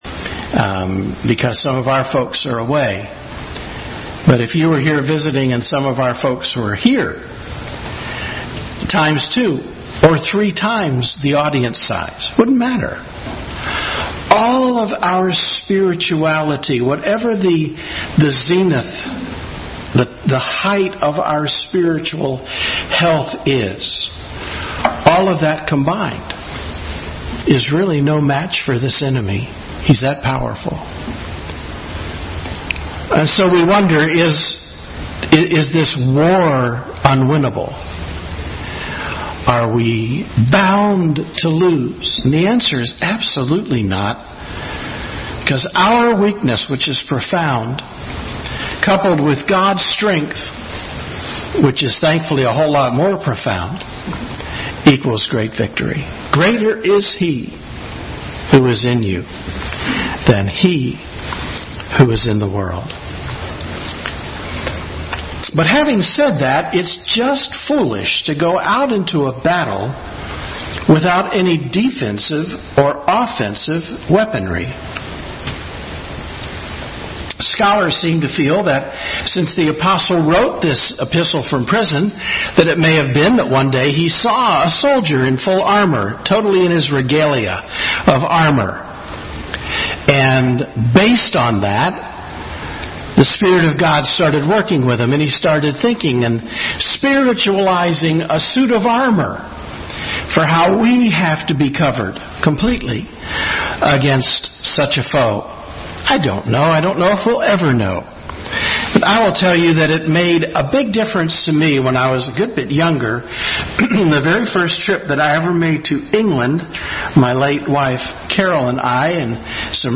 Bible Text: Ephesians 6:10-20 | Family Bible Hour message on the whole armor of God.